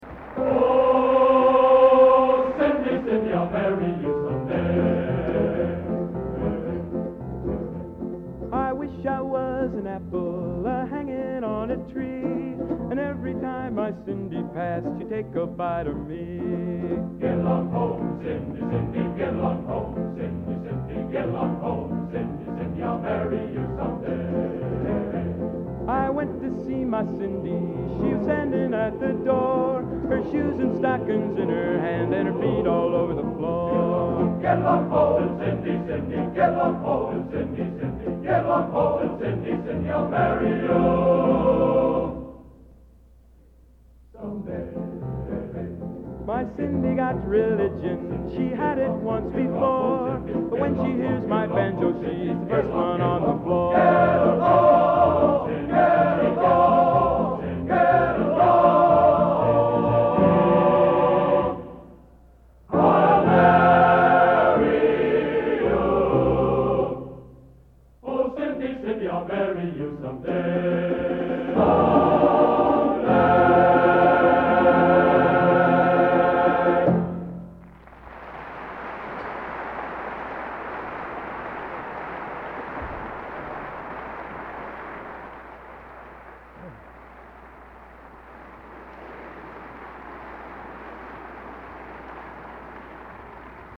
Collection: Gala Concert, 1961
Genre: Popular / Standards | Type: Solo